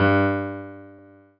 piano-ff-23.wav